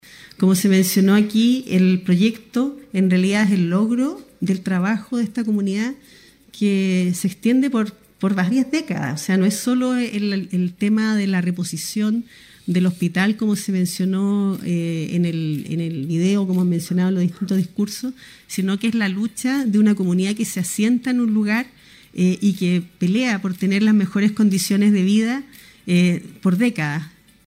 Durante la ceremonia, la ministra de la cartera de Salud, Ximena Aguilera, destacó la importancia del proyecto.
ministra-de-salud-queilen-1-.mp3